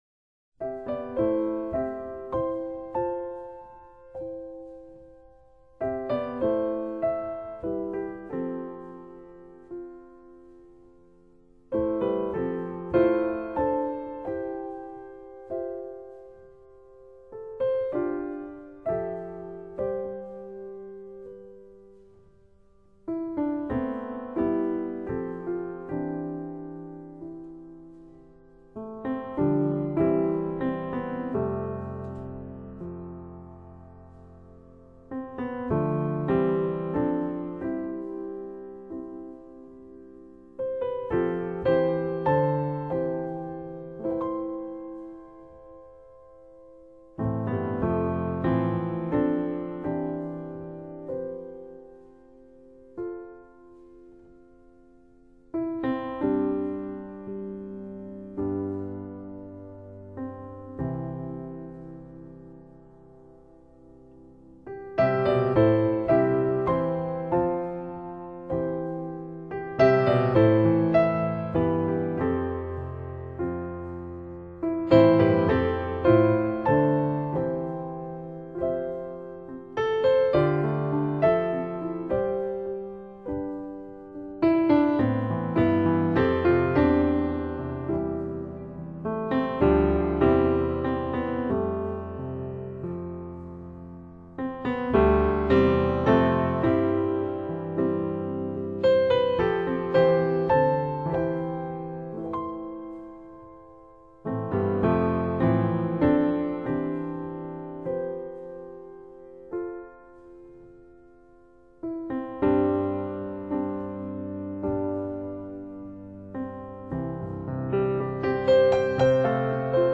纯美钢琴
素雅琴音流轉而出　迷人悠境隨處可得
以鋼琴獨奏方式呈現 10 首全新創作曲
，清新澄澈的音色緩緩縈迴空氣中，就像純白色般單純而又舒服的感覺，使繁忙的您自然地在這些